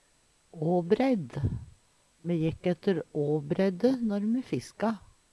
DIALEKTORD PÅ NORMERT NORSK åbreidd elvekant Infinitiv Presens Preteritum Perfektum åbreidd åbreidde Eksempel på bruk Me jekk ætte åbreidde når me fiska.